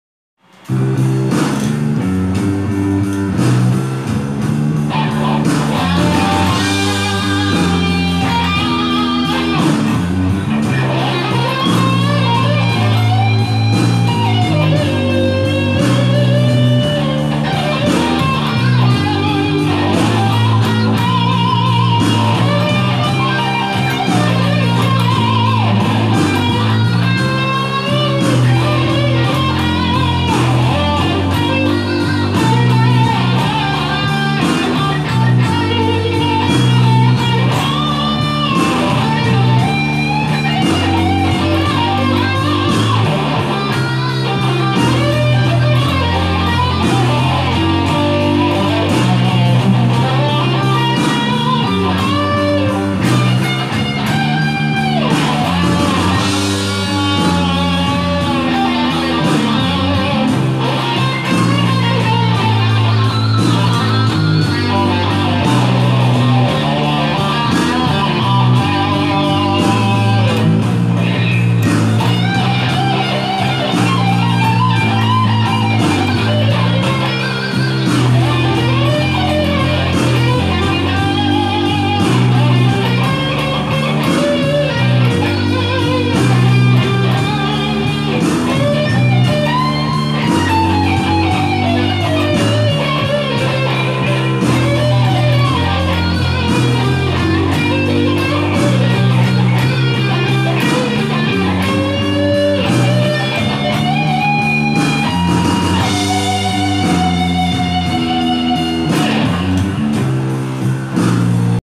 blues improvisation performance imagine brazil 2017